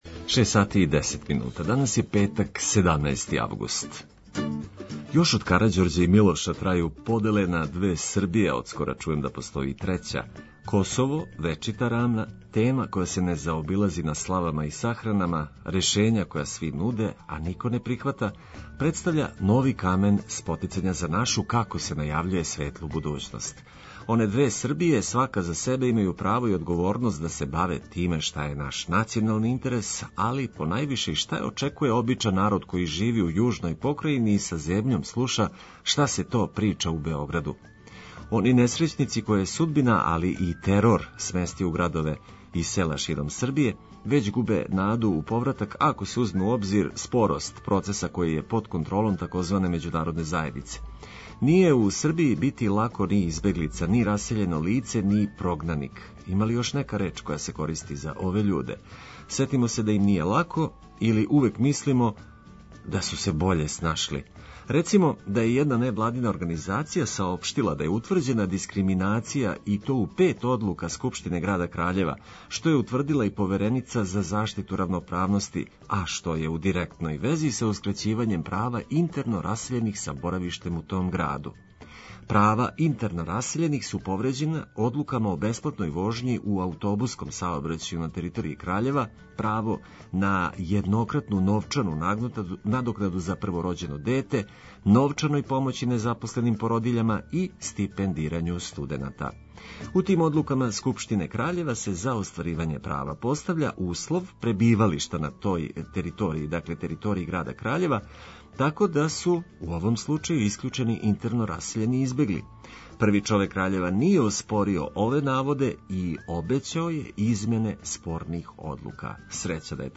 Приче од користи за све који нас слушају и само хитови за расањивање.